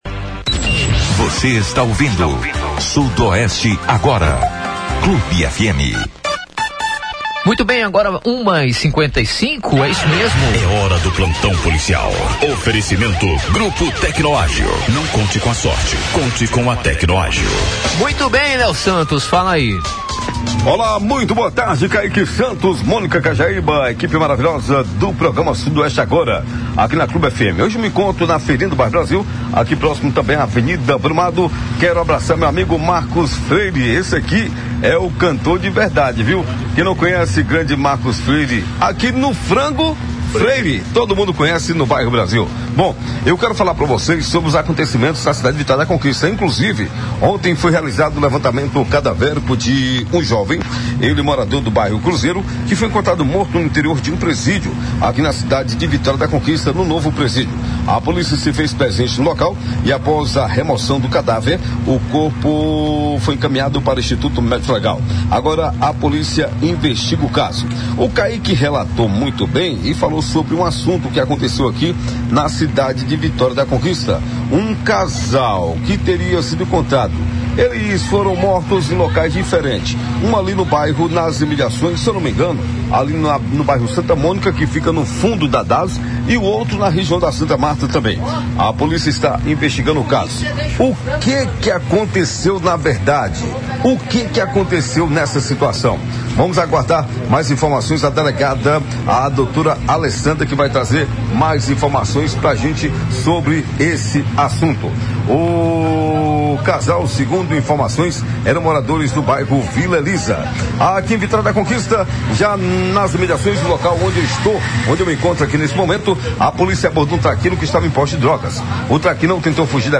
As denúncias de um suposto esquema de “rachadinha” na Câmara Municipal de Vitória da Conquista ganharam destaque na imprensa estadual e foram tema central de debate no programa Brasil Notícias, da Rádio Brasil, nesta terça-feira (14).